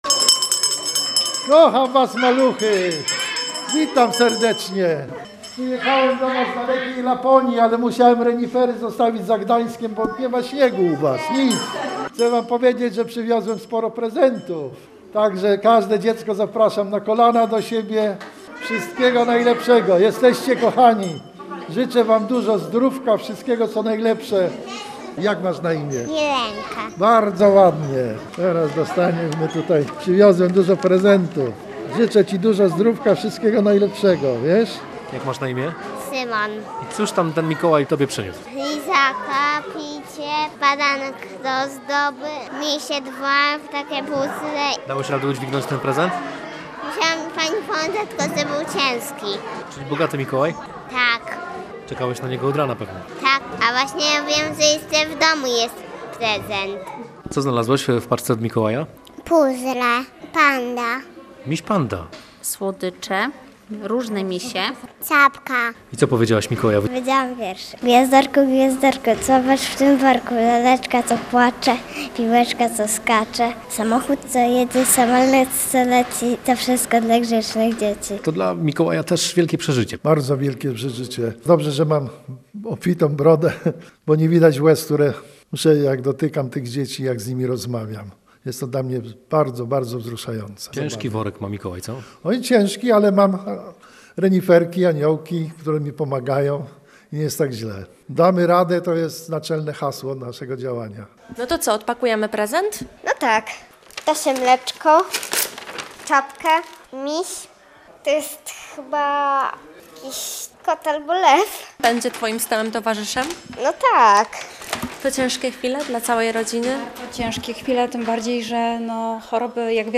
Tak dzieci cieszyły się z wizyty Mikołaja w gdańskich szpitalach: